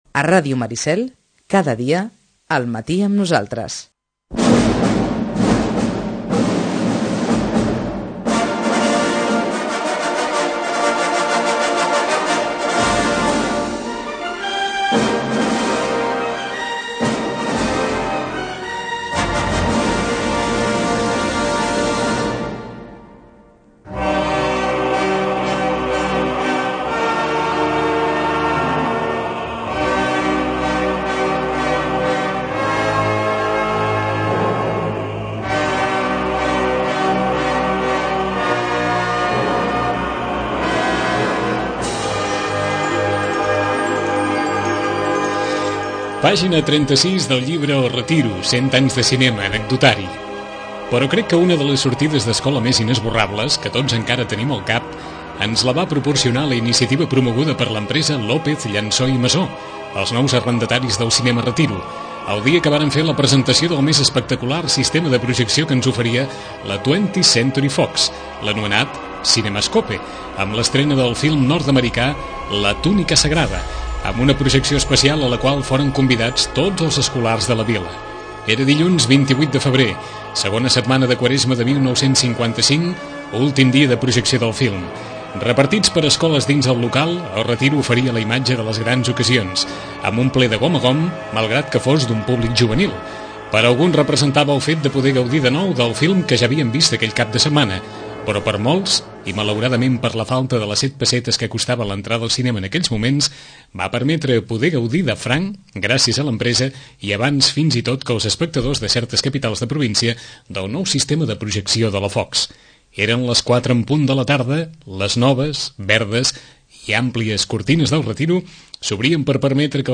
Presentació del llibre del centenari del cinema El Retiro